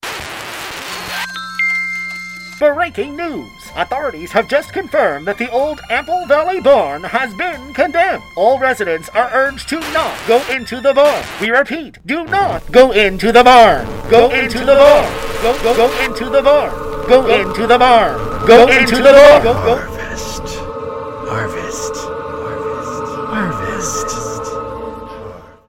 Each world intricately recreates familiar scenes laid out exactly as they once were, brought back to life through theatrical lighting transitions and their own iconic background music tracks, each leading with a thematic mini-preshow introductory announcement.